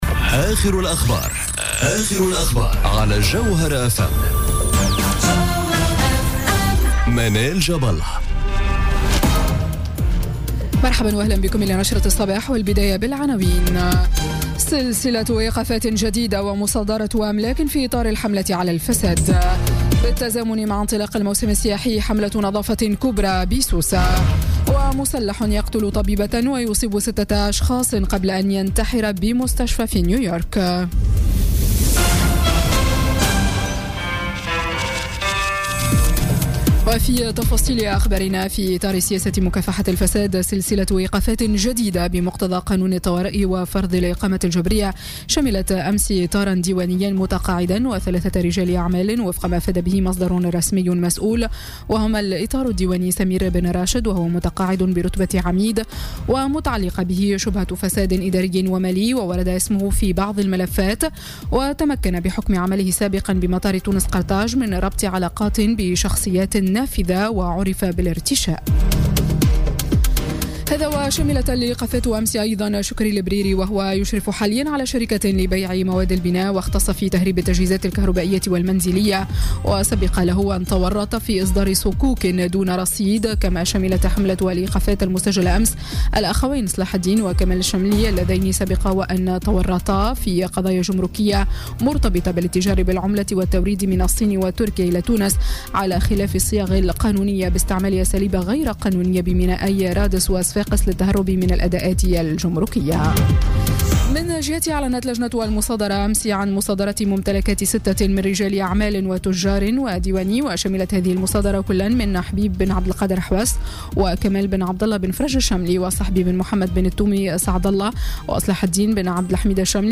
نشرة أخبار السابعة صباحا ليوم السبت غرّة جويلية 2017